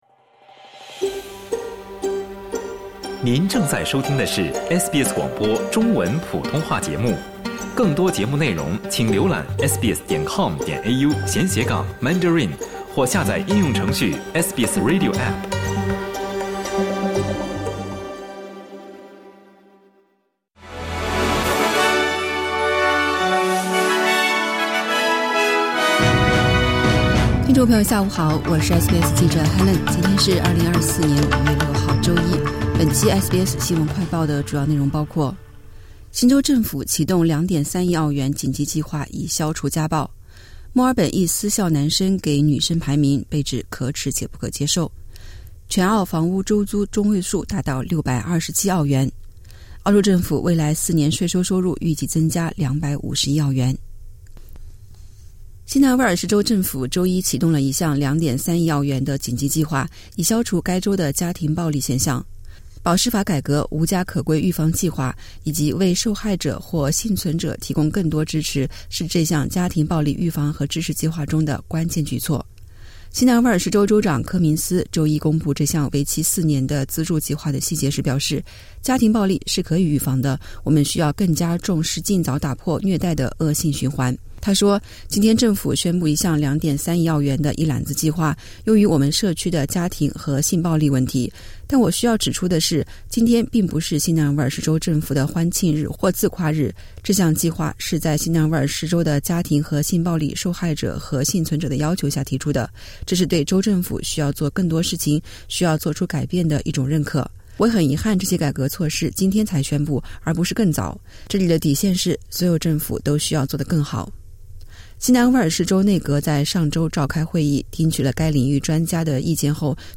新闻快报